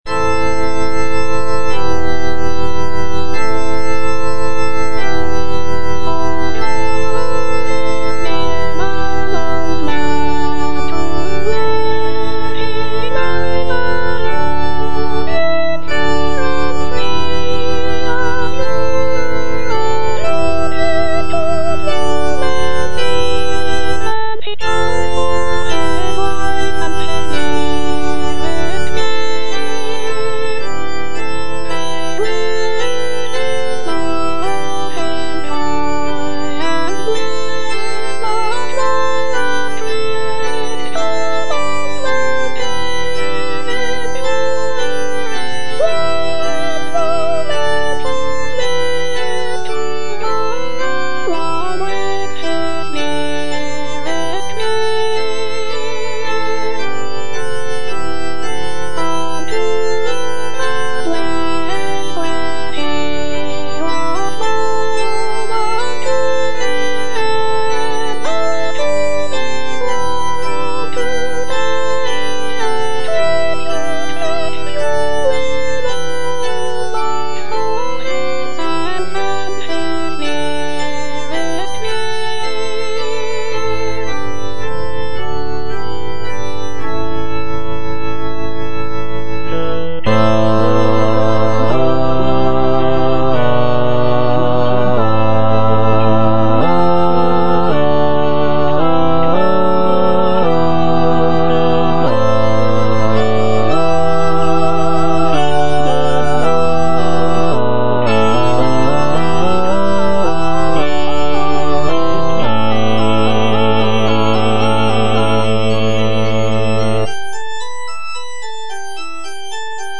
Bass (Emphasised voice and other voices) Ads stop